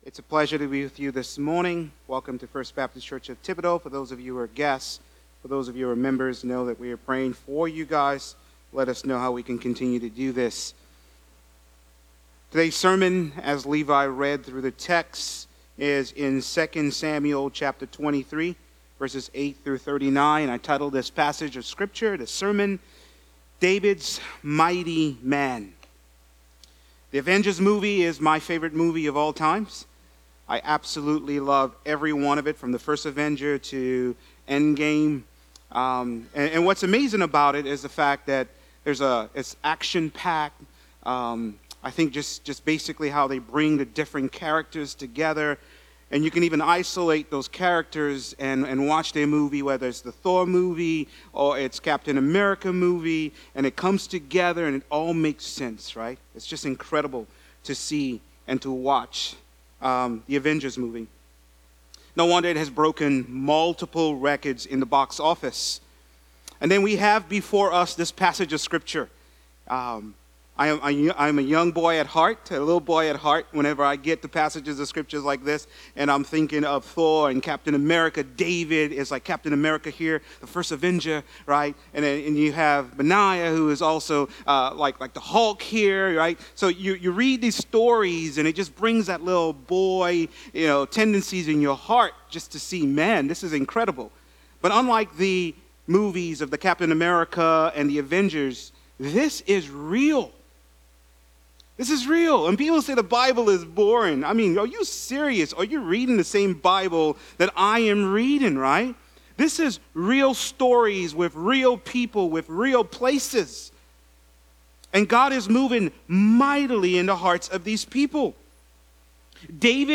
Sermons – FBC Thibodaux